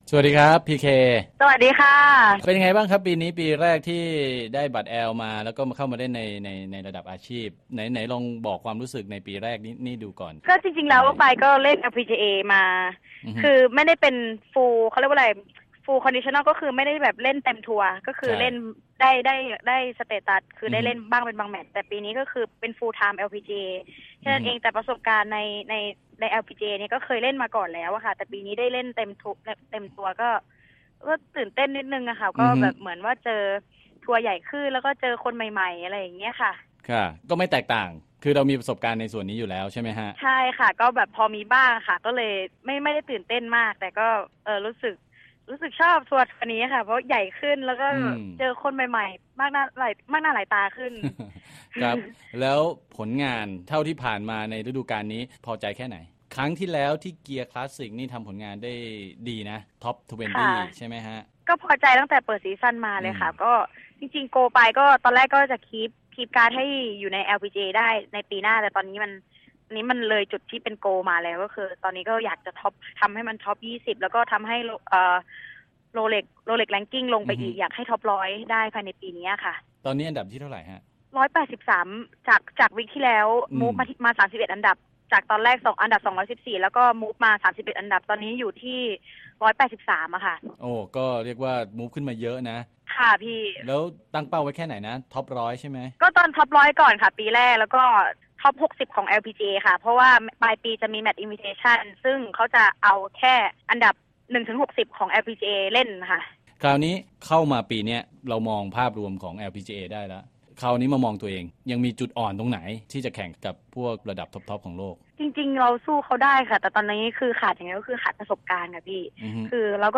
Interview Thai Golfer LPGA